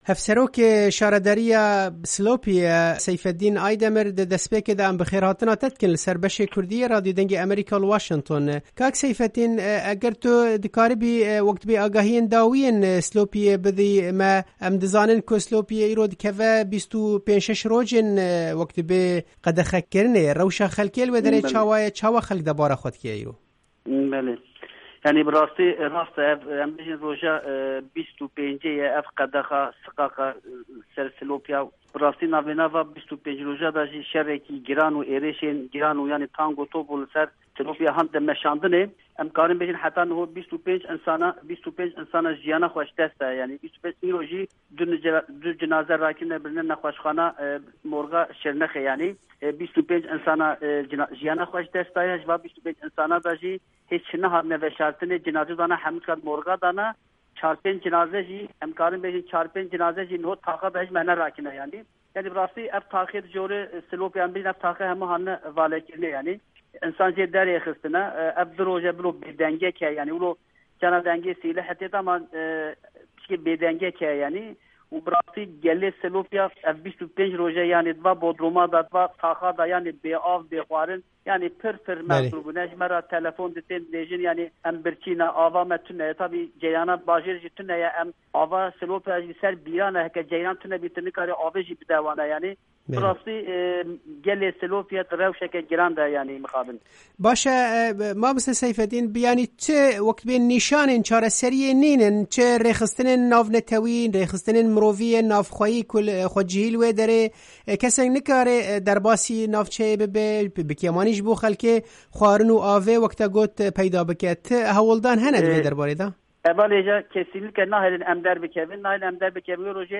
Hevserokê şaredariya Slopî Seyfettîn Aydemir di hevpeyvînekê de li gel Dengê Amerîka rewşa naha ya bajarê Slopî şîrove kir û got: